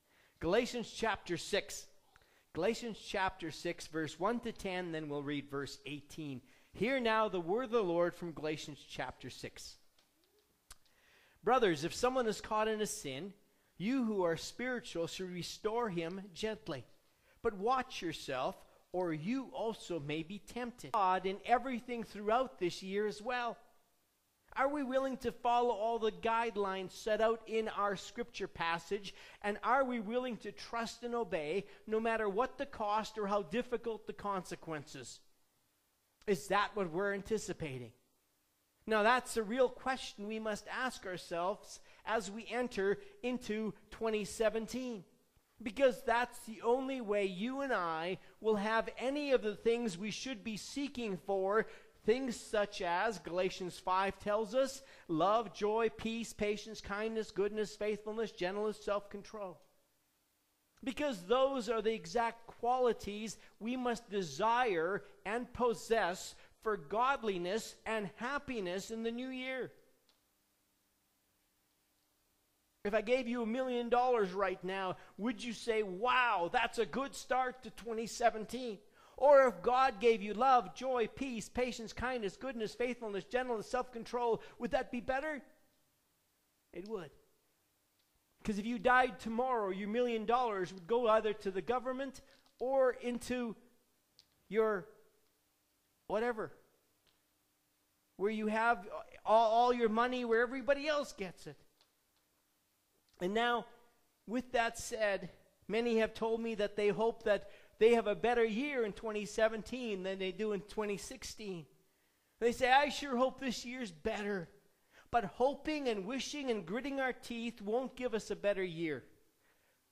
A Sermon from New Year 2017, but applicable today.